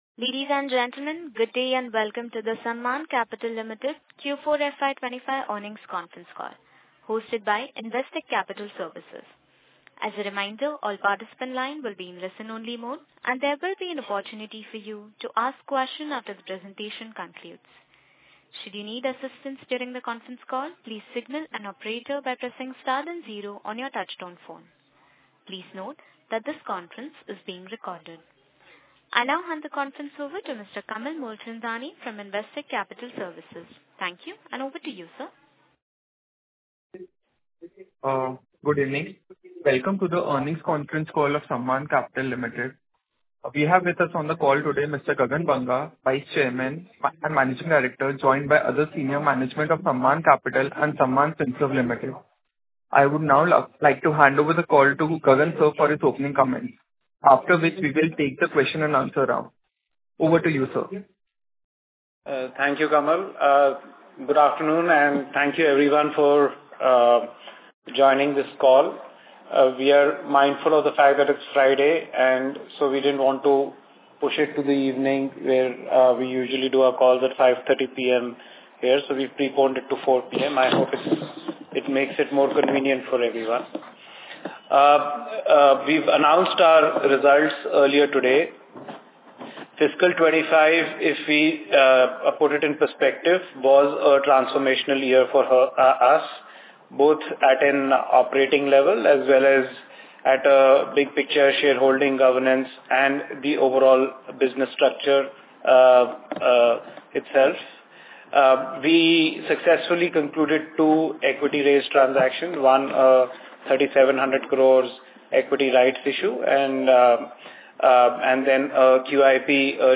Q4FY25 ಗಳಿಕೆಗಳಿಗಾಗಿ ಹೂಡಿಕೆದಾರರ ಕಾಲ್